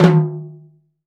T11T  FLAM.wav